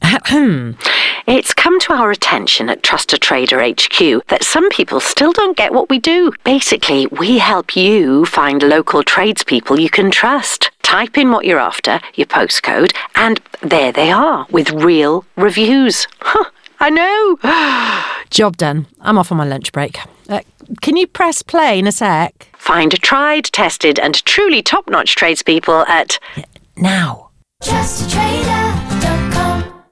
TrustATrader delivers powerful national exposure through major UK television campaigns, with all TV and radio advertising voiced by the acclaimed Ruth Jones.
Ruth Jones is the new Voice of TrustATrader!
trustatrader-radio-advert.mp3